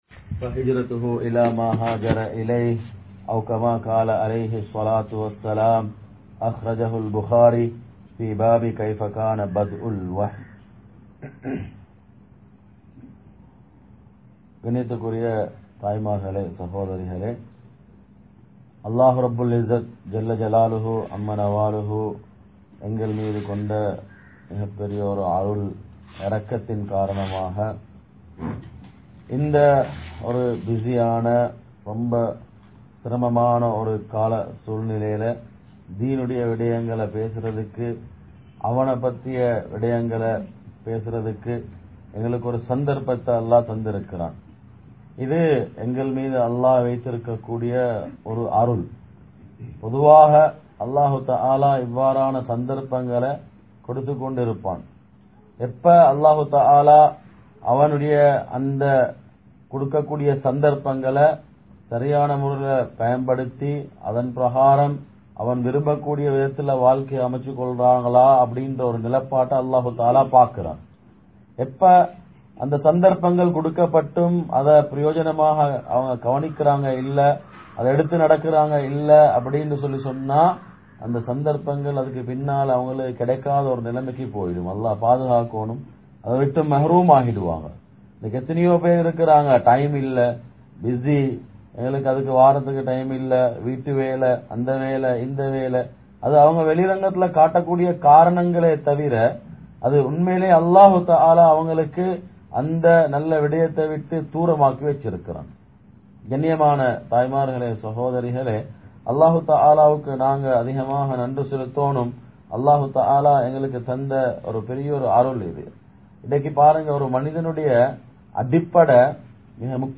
Kulanthai Valarpu (குழந்தை வளர்ப்பு) | Audio Bayans | All Ceylon Muslim Youth Community | Addalaichenai